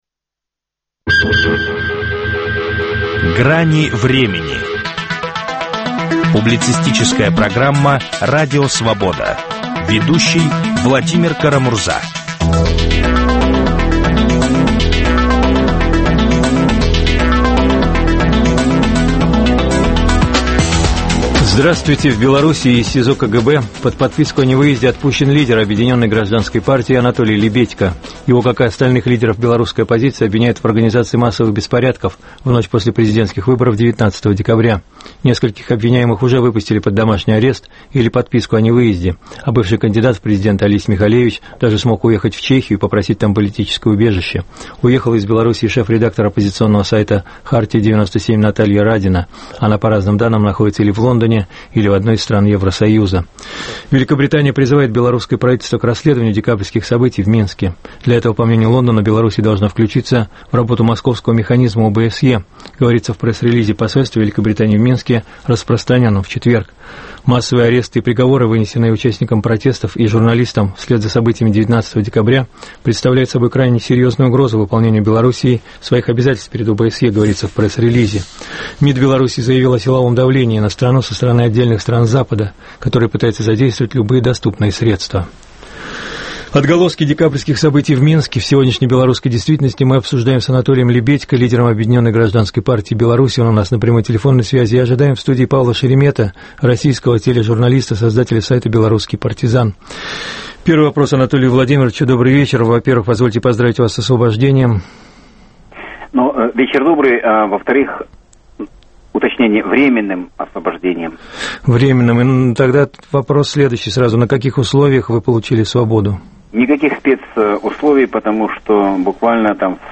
Отголоски декабрьских событий в Минске в сегодняшней белорусской действительности обсуждают политик Анатолий Лебедько и журналист Павел Шеремет.